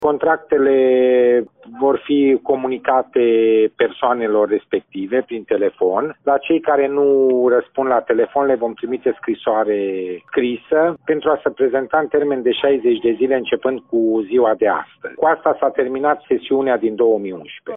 Directorul Agenției pentru Protecția Mediului Mureș, Dănuț Ștefănescu.